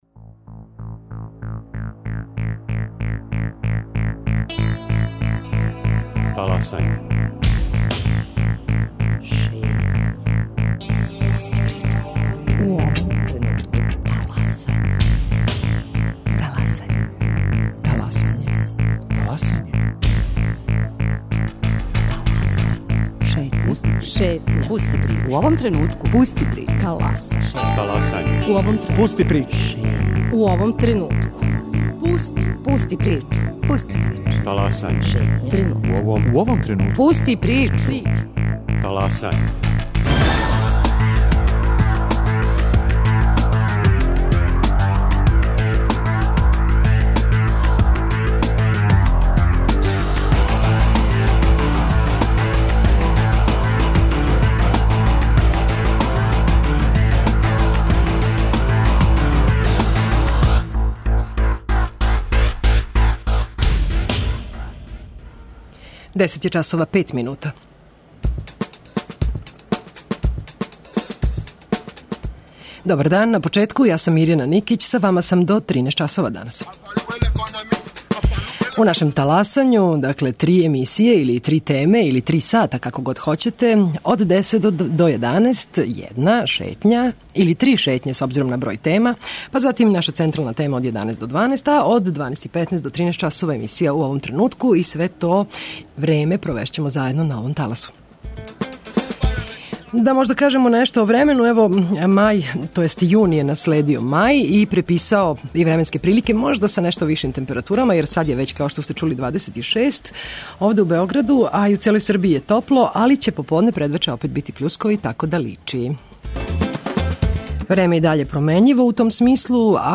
Гости Шетње су чланови удружења Микроарт.